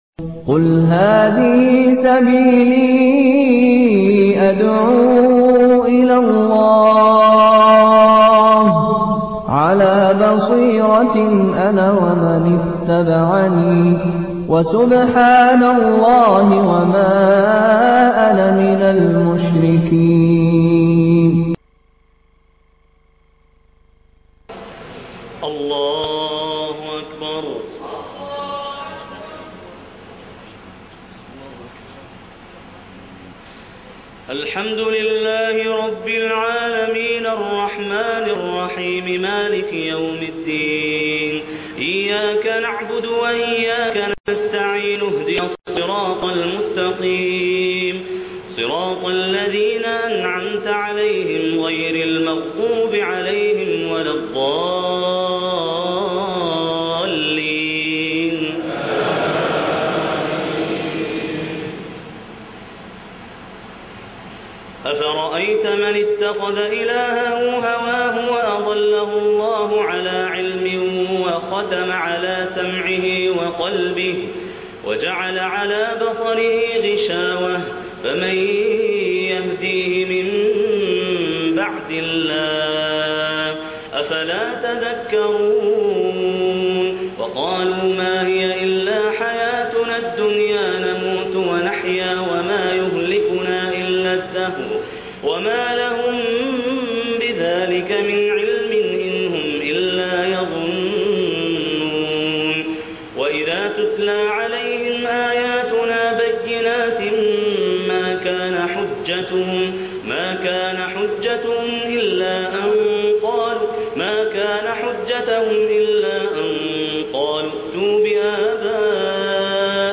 صلاة التراويح من المسجد الأقصى (ليلة 23 رمضان) 1432 هـ - قسم المنوعات